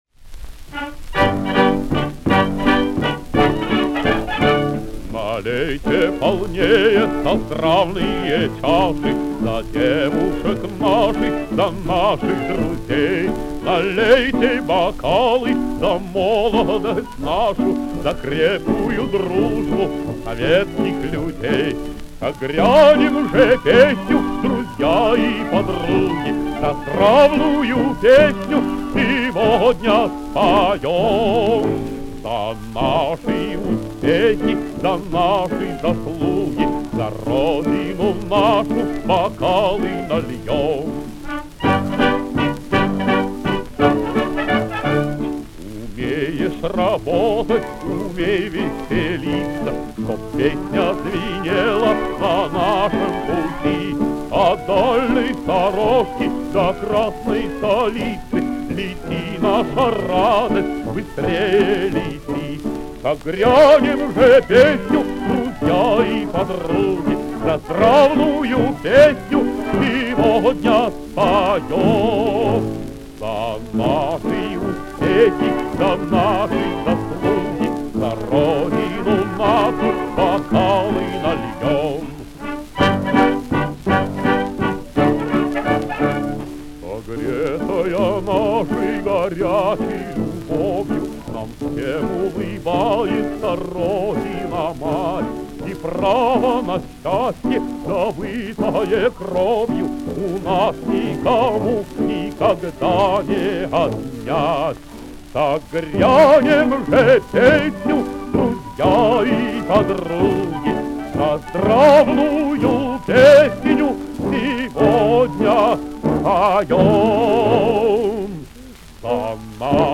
джаз-ансамбль